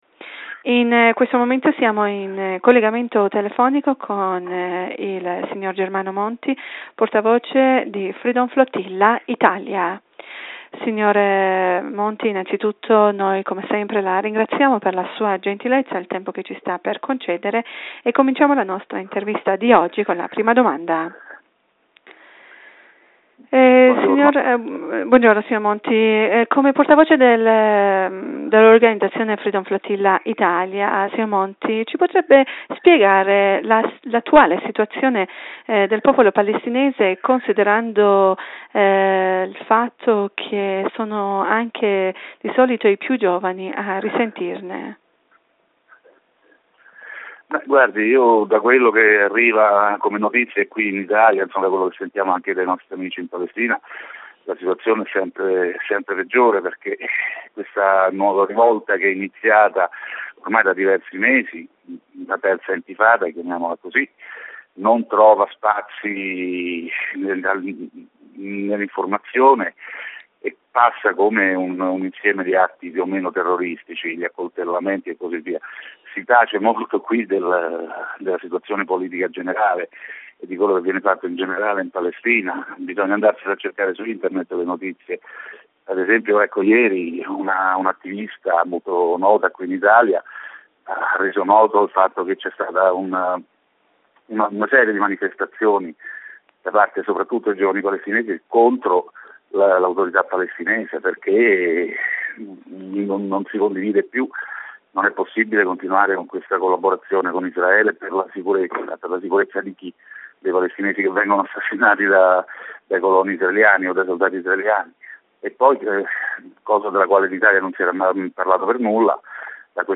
in un'intervista telefonica